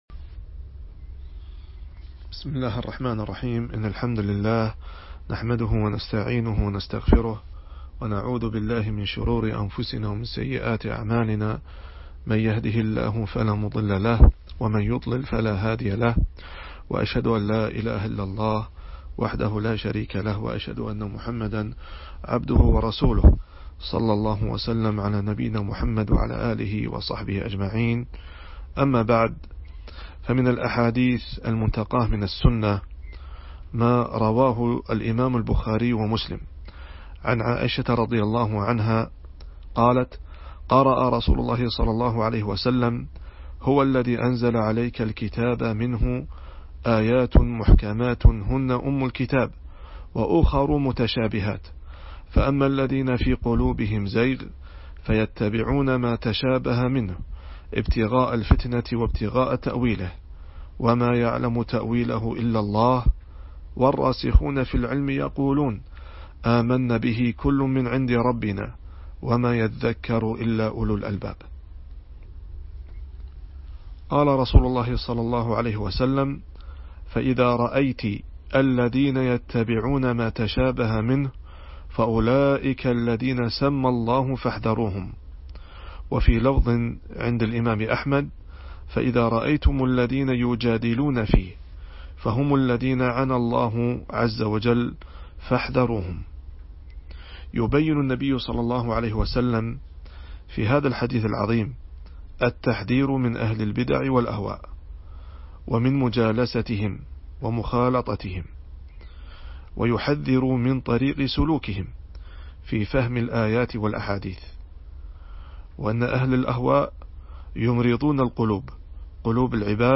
الأحاديث المنتقاة من السنة - الدرس الثاني